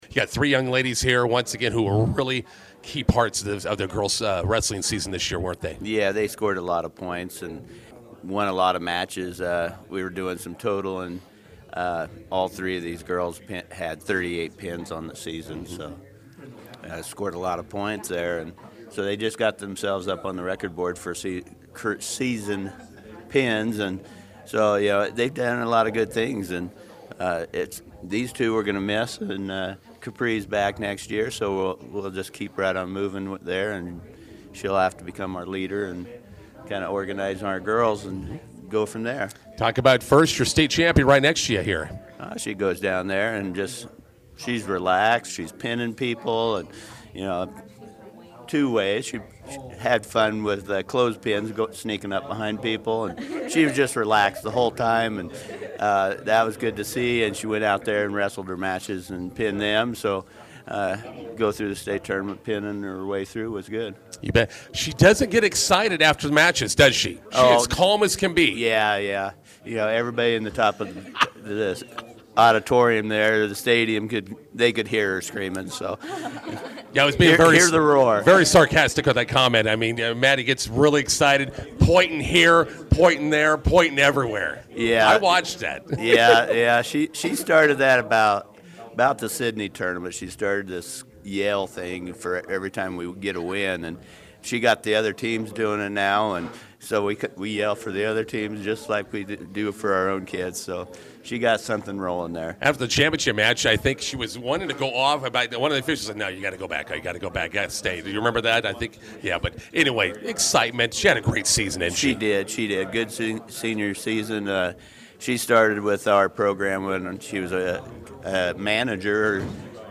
INTERVIEW: Southwest girls wrestling finish in Class B state tournament top ten.